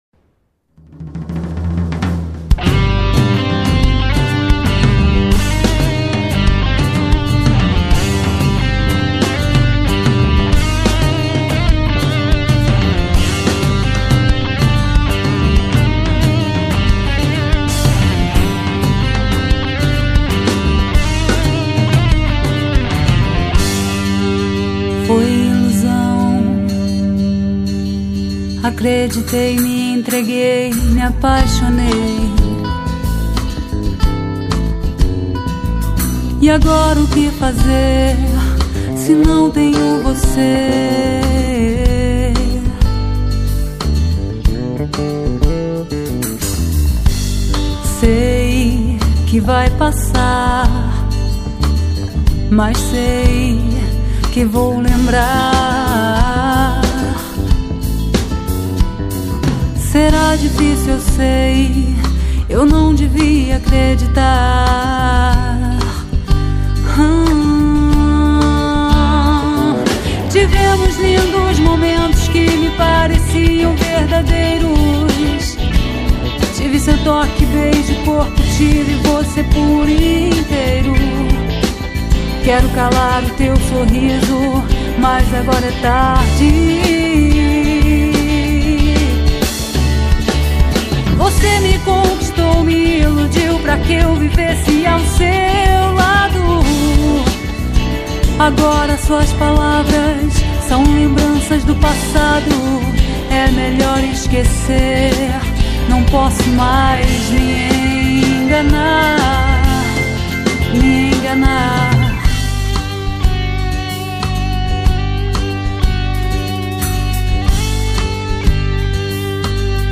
EstiloPop